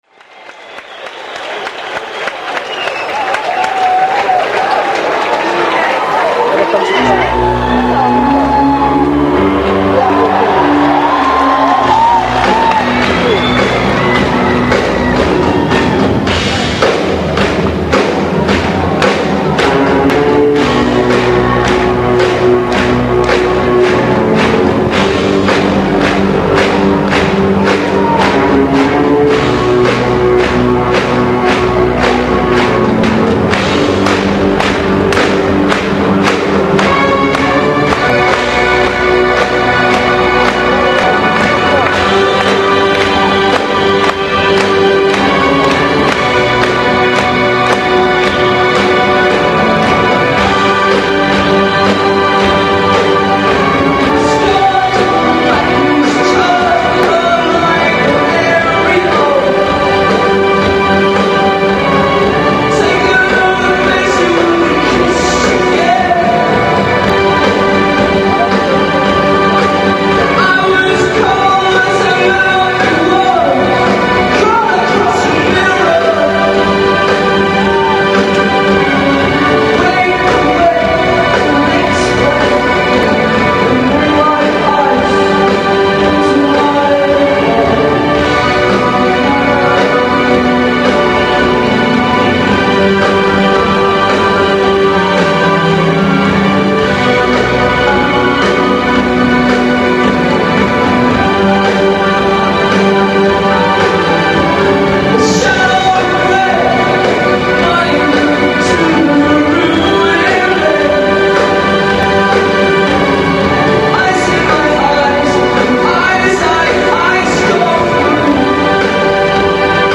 Koncert w Katowicach 15.11.96